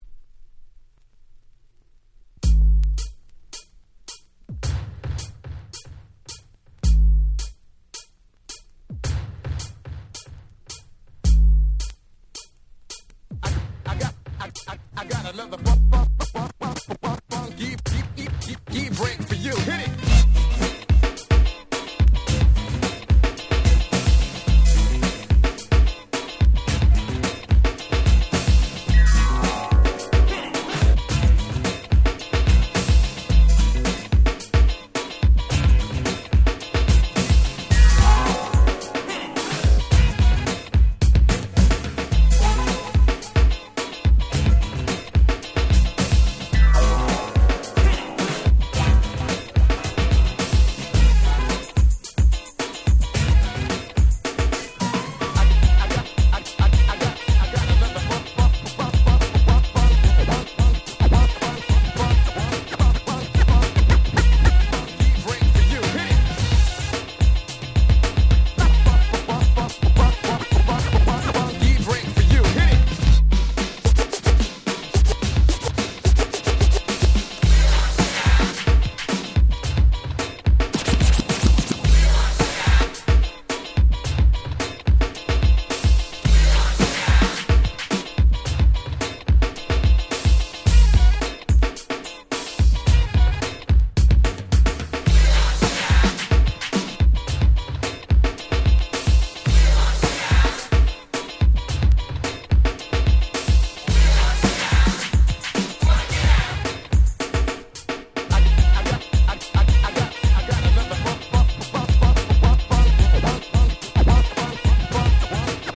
88' Old School / Middle School Classics !!
80'sのHip Hopは最近どんどん入手し辛くなっております。